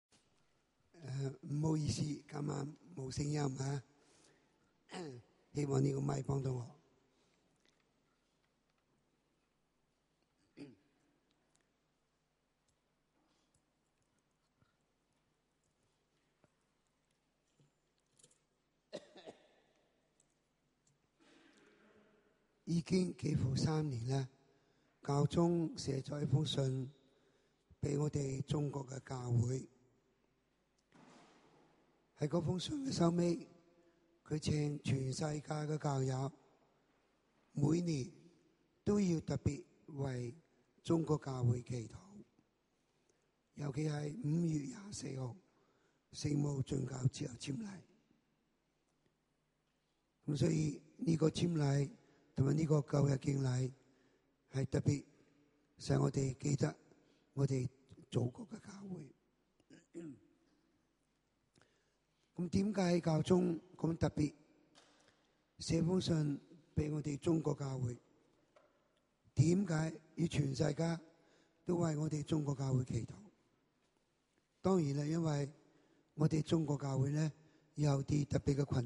陳樞機講解禮儀